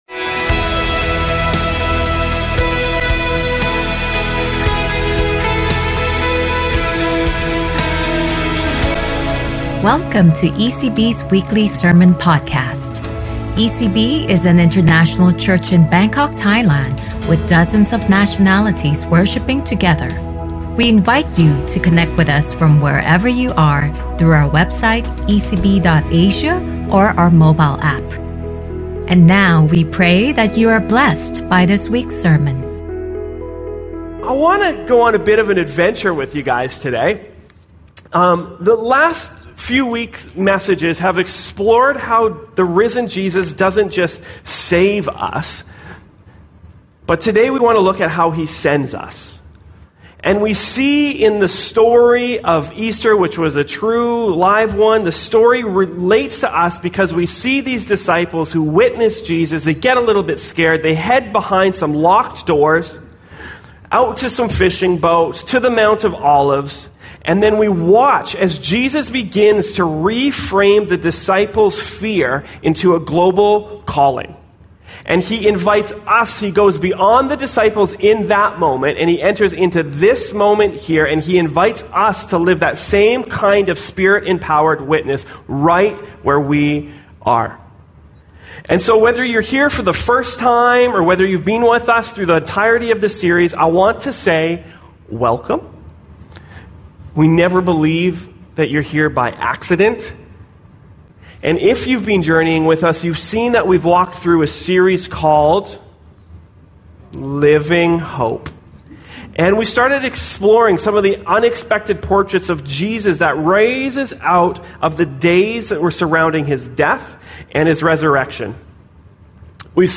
ECB Sermon Podcast